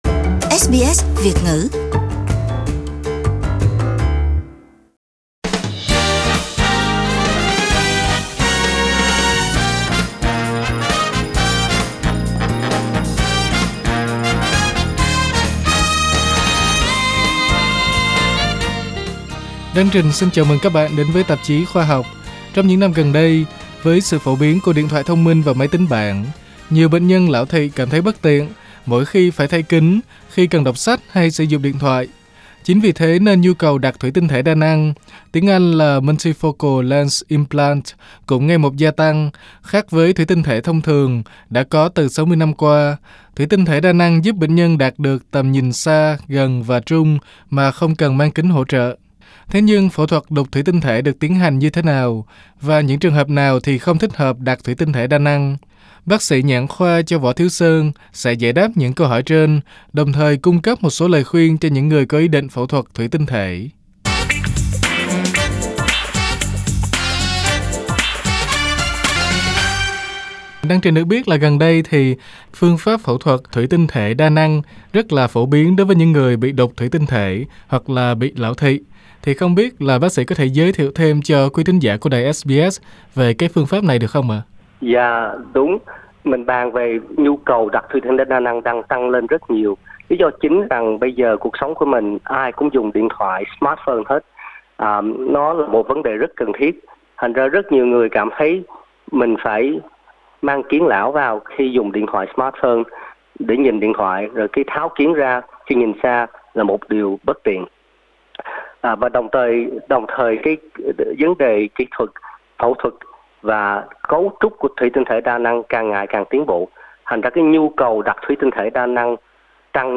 Bác sĩ nhãn khoa